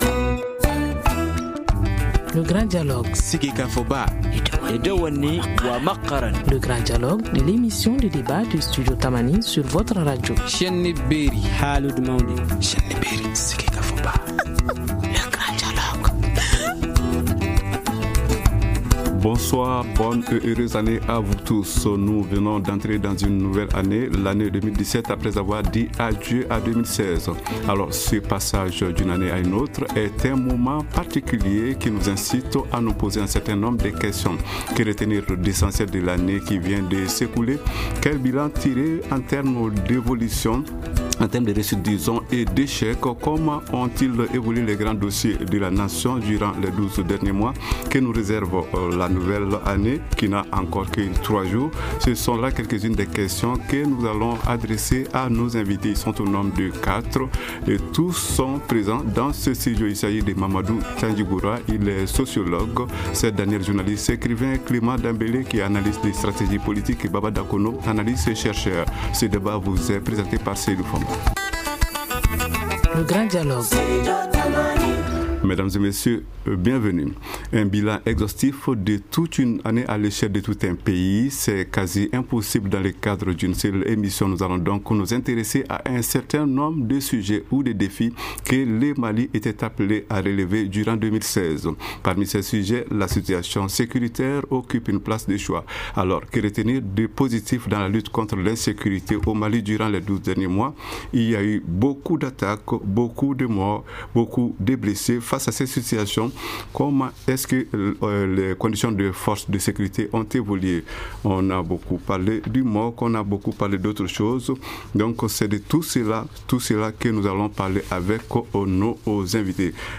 Ce sont quelques-unes des questions que nous allons adresser à nos invités de ce soir.